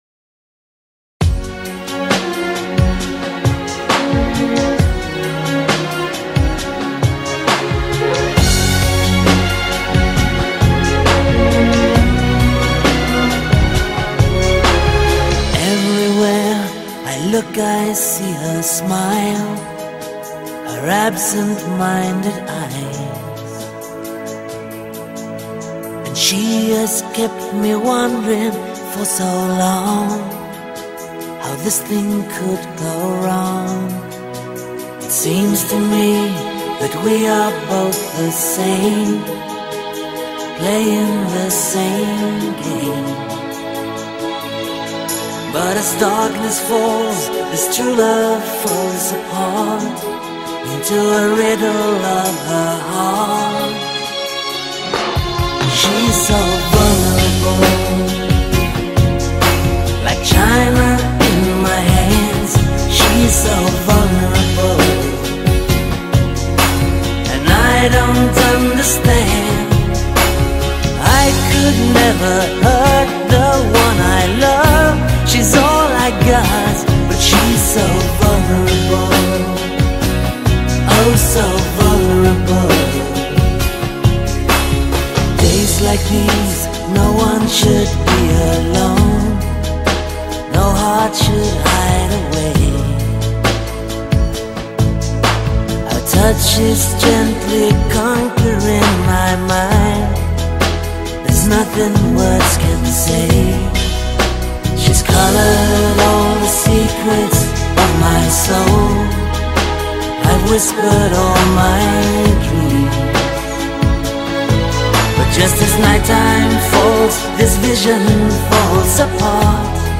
(Relaxing, Enjoying, Peace of Mind, Lullaby, and Memorable)